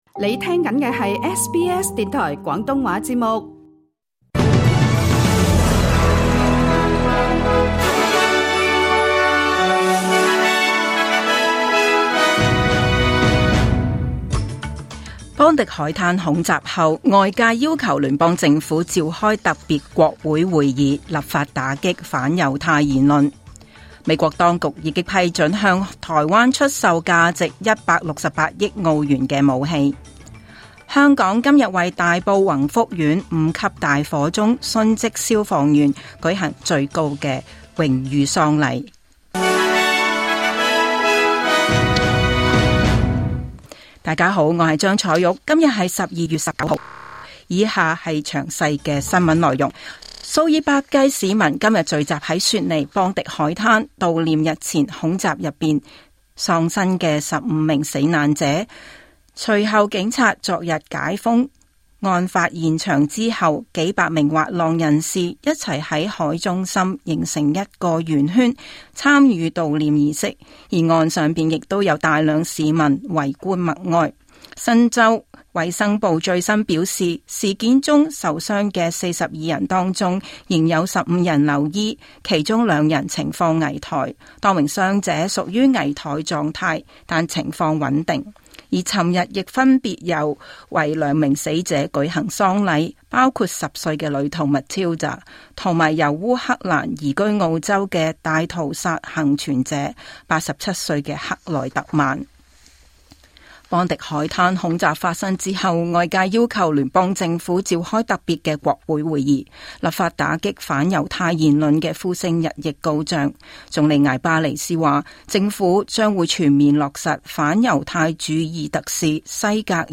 2025 年 12 月 19 日 SBS 廣東話節目詳盡早晨新聞報道。